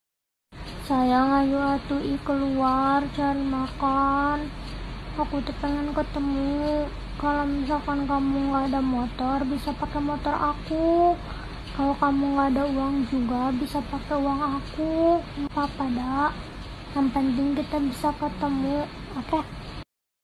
Voice note so sweet bikin baper
Kategori: Suara manusia
voice-note-so-sweet-bikin-baper-id-www_tiengdong_com.mp3